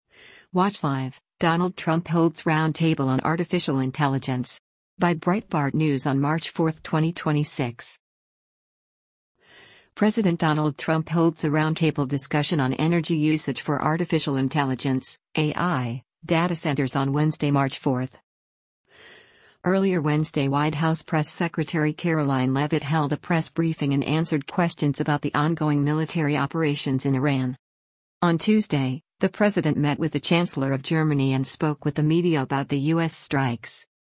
President Donald Trump holds a roundtable discussion on energy usage for artificial intelligence (AI) datacenters on Wednesday, March 4.